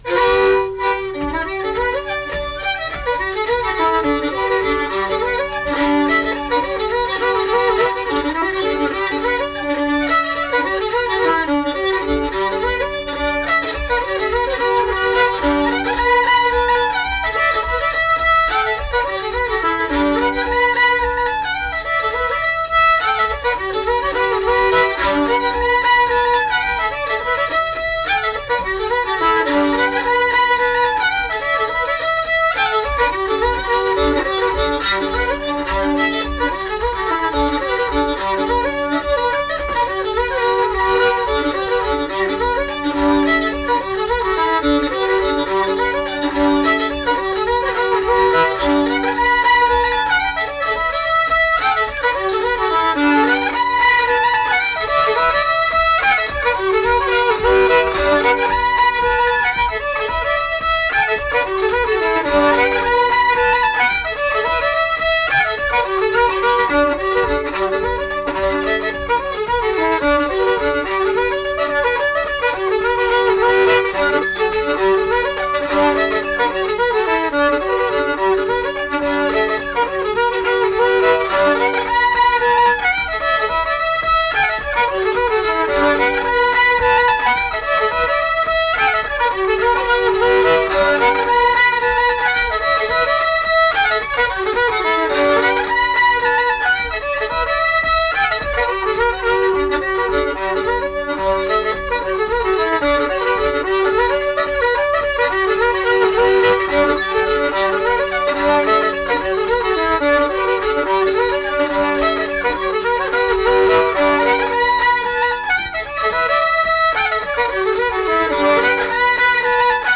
Accompanied guitar and unaccompanied fiddle music performance
Verse-refrain 8(2).
Instrumental Music
Fiddle